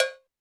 Drums_K4(21).wav